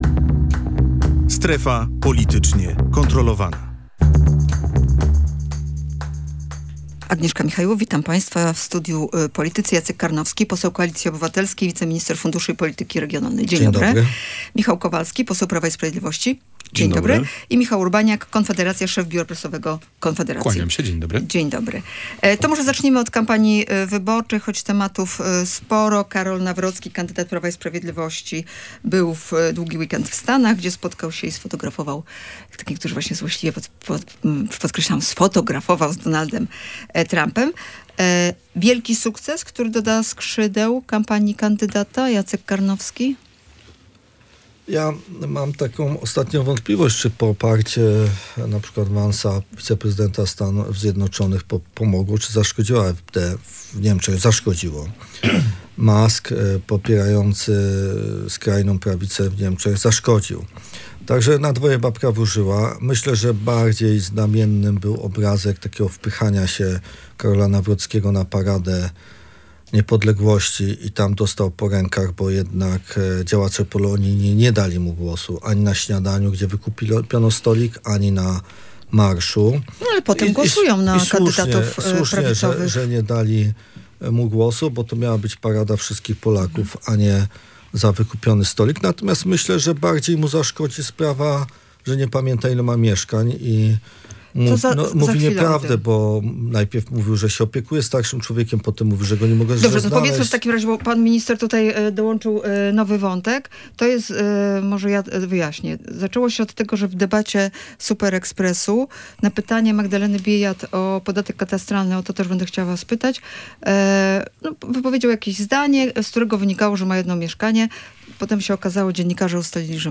Energa będzie firmą rozwijającą się i będzie firmą w Gdańsku. Takie słowa usłyszałem od prezesa Orlenu – mówił na naszej antenie Jacek Karnowski.